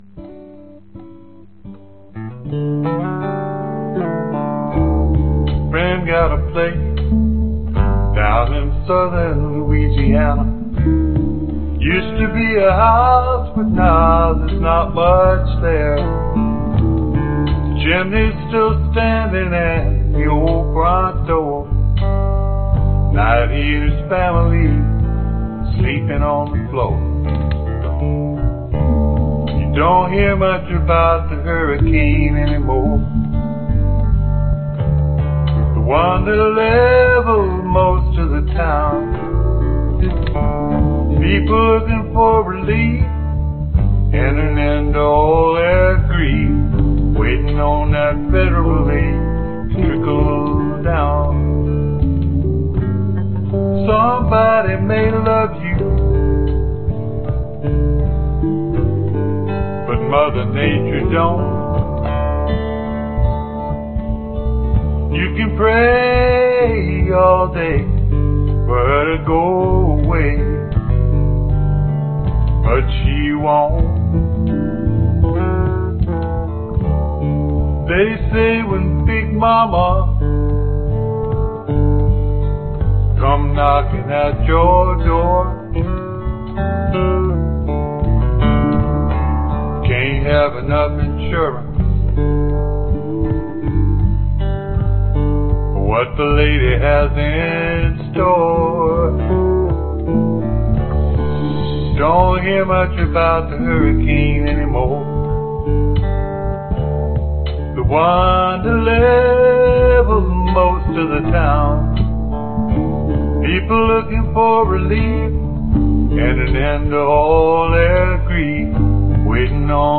标签： 女性主唱 吉他 贝斯 风琴 钢琴 慢板 Trip_hop 合成器
声道立体声